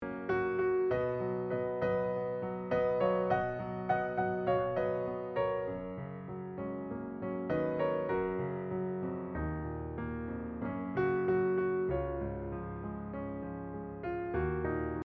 piano interpretations